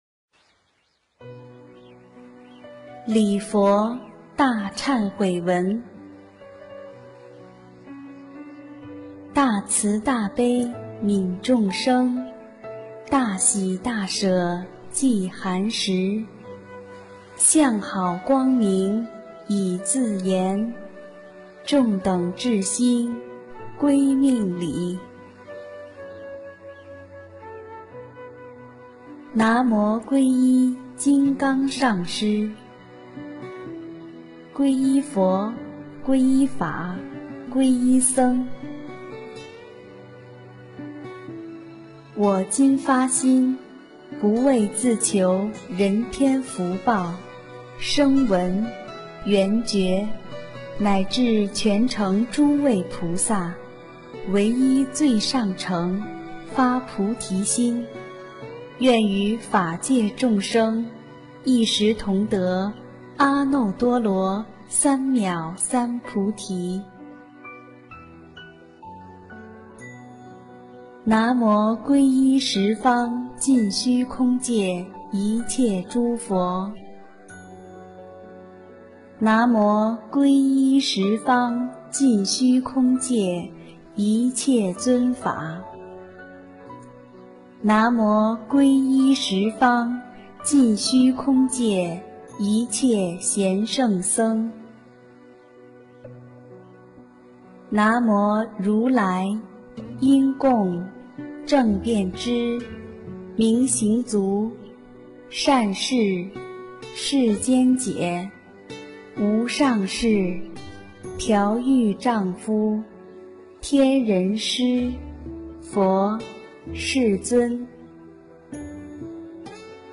首页 >>佛法书籍 >> 英文·经文教念